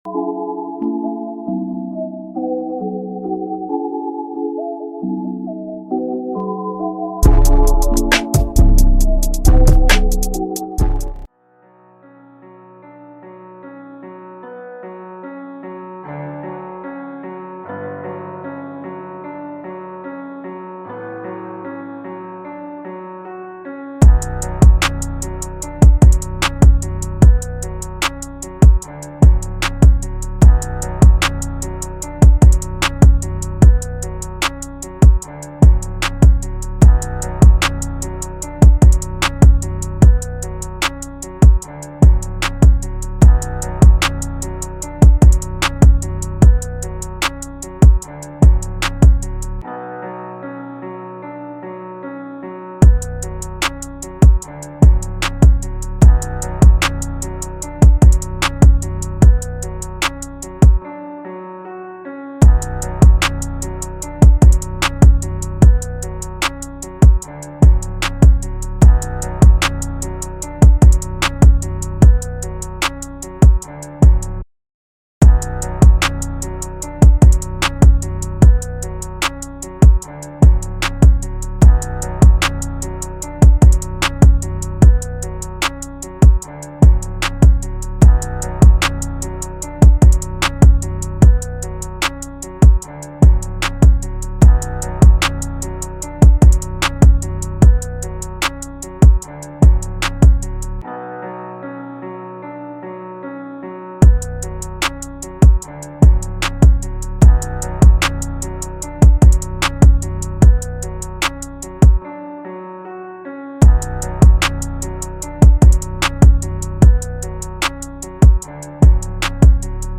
freestyle instrumental